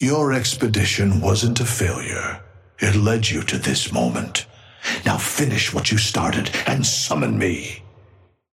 Patron_male_ally_kelvin_start_01.mp3